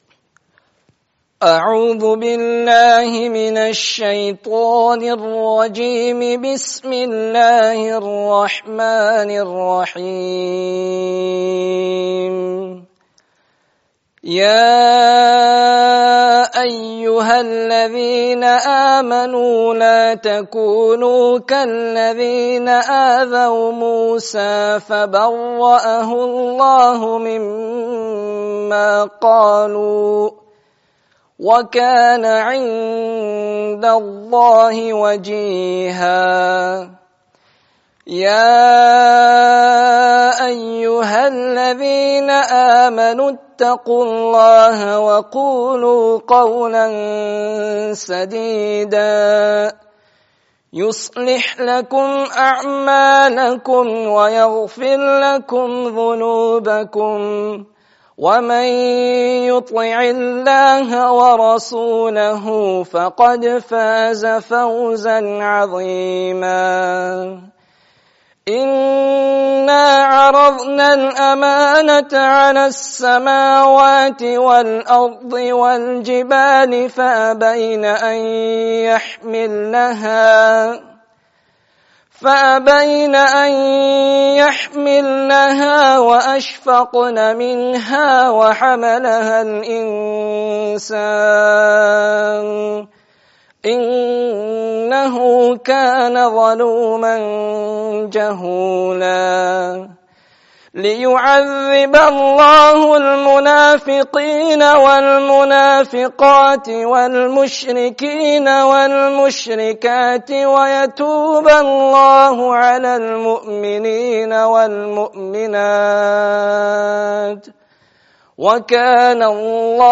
Kyā Me(n) Haqīqat me(n) 'Āshiqe Rasūl ﷺ hu(n)? (Nikah 27/03/10)